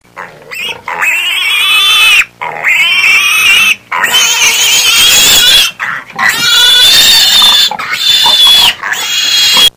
Другие рингтоны по запросу: | Теги: свинья, визг